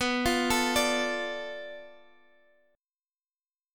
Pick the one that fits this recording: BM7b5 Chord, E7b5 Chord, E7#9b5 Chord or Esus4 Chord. BM7b5 Chord